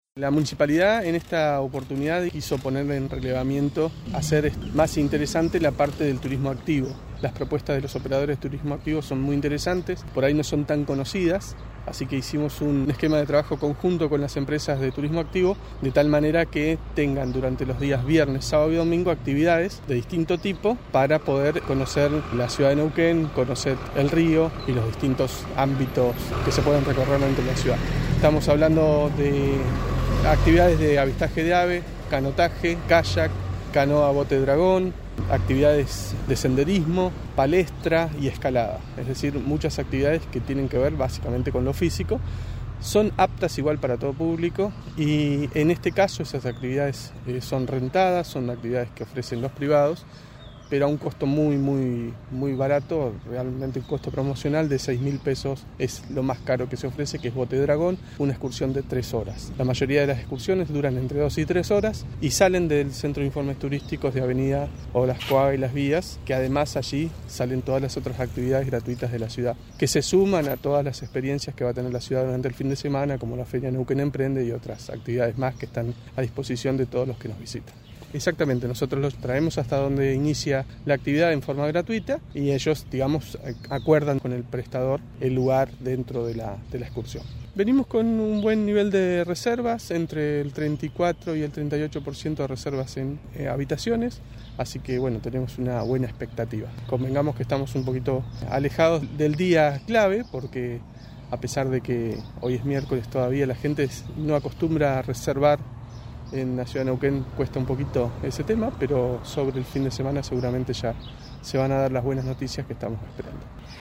Diego Cayol, secretario de Turismo y Desarrollo Social.